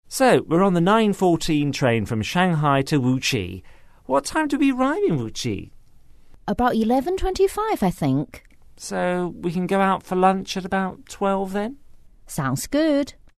英语初学者口语对话第75集：火车几点到无锡呢？
english_12_dialogue_2.mp3